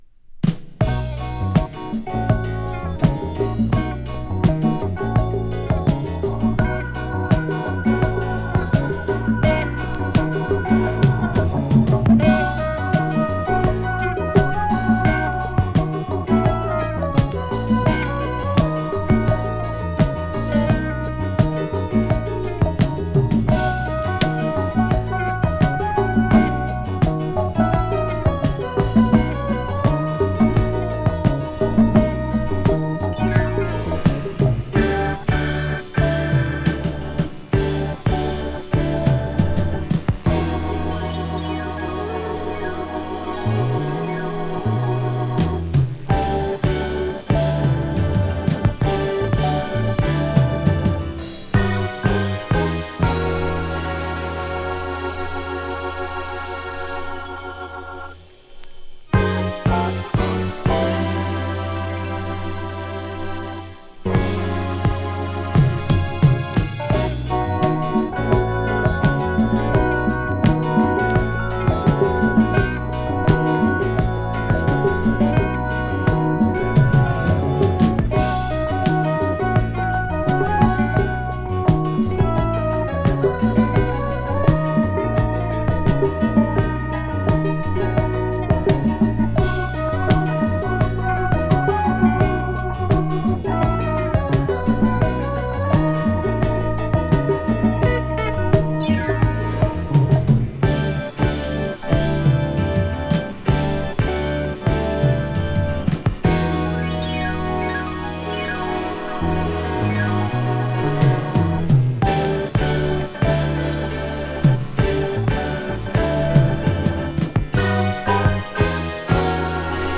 激シブいディスコ・フュージョン!!